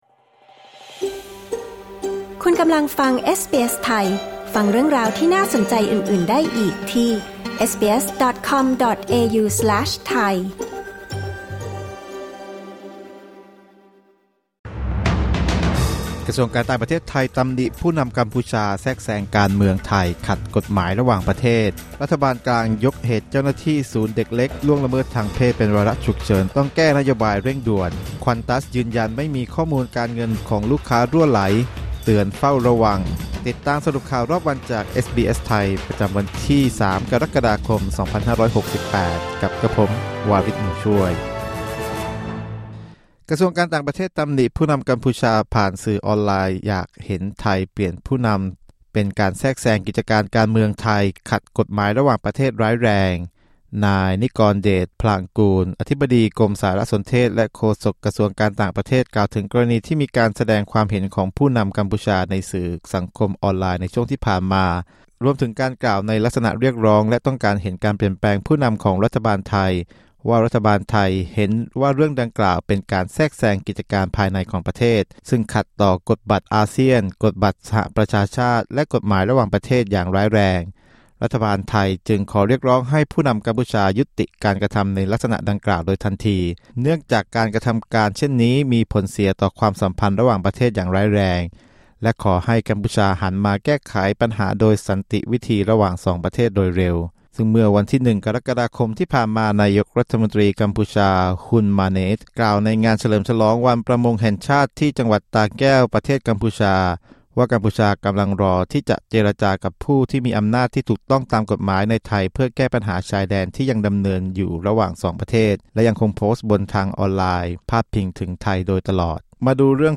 สรุปข่าวรอบวัน 03 กรกฎาคม 2568